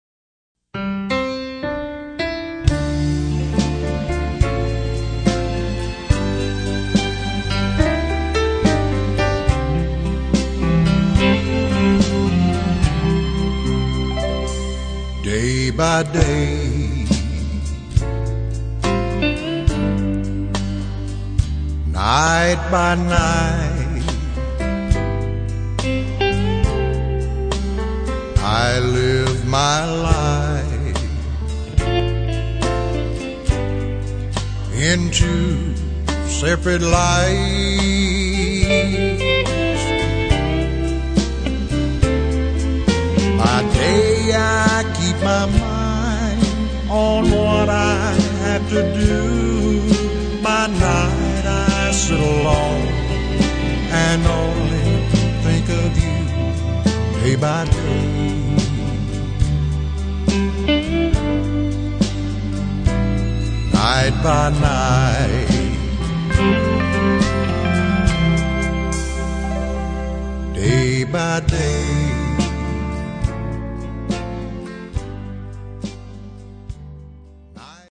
An original ballad style song